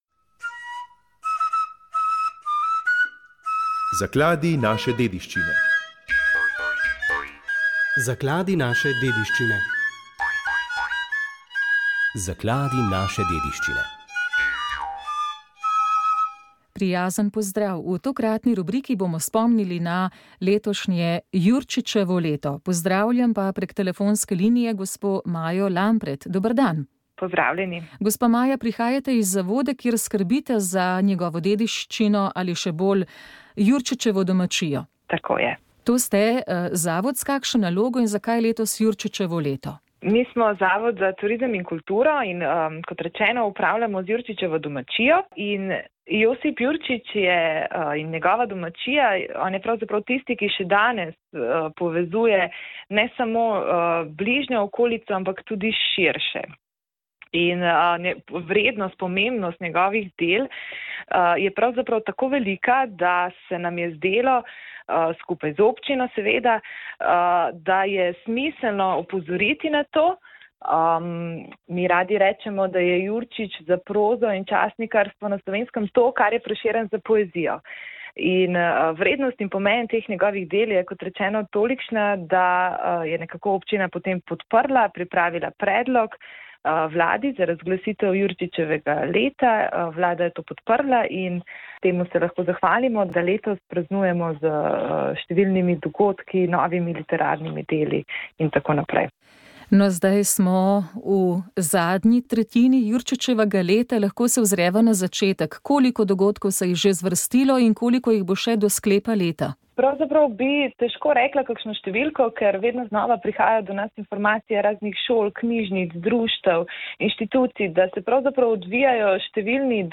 Ker mineva 140 let od smrti pisatelja, pesnika in časnikarja ter avtorja prvega slovenskega romana, je Vlada leto 2021 razglasila za leto Josipa Jurčiča, ki je bil ena od osrednjih kulturnih in političnih oseb druge polovice 19. stoletja. Pogovarjali smo se